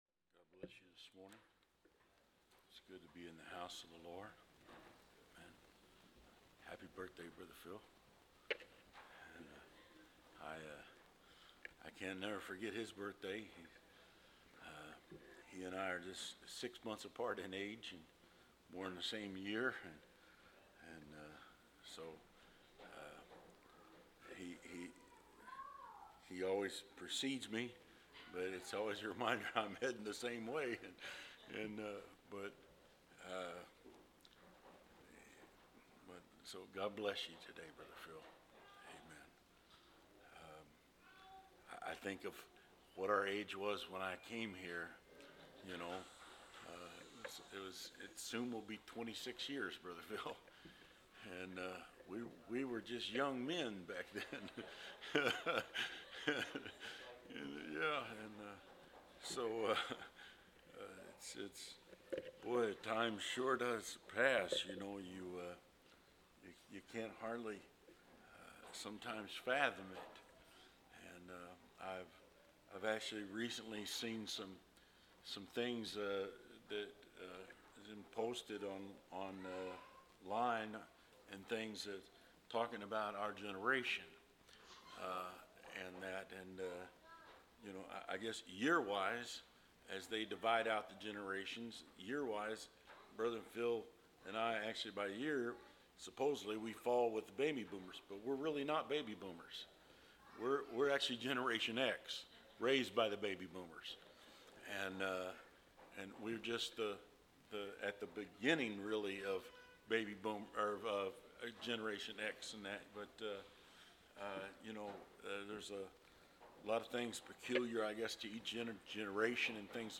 Communion